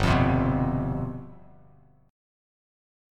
Listen to G11 strummed